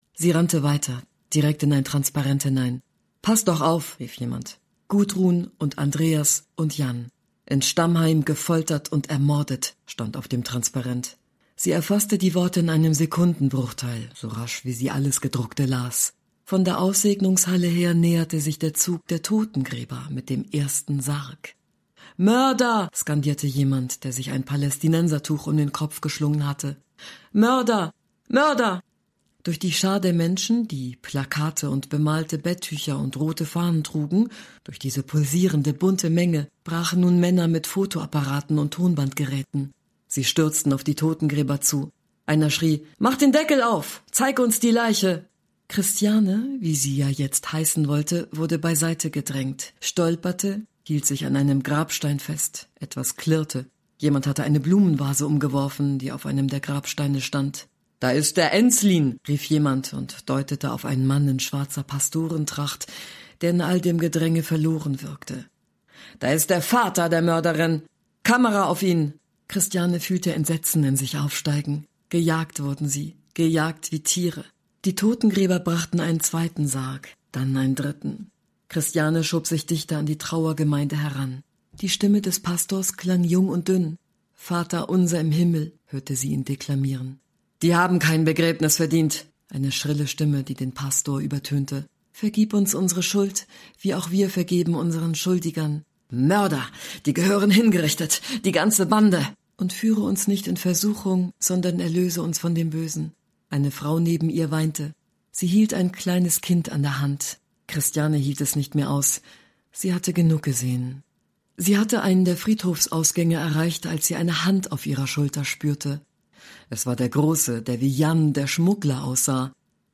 Hörprobe Belletristik 1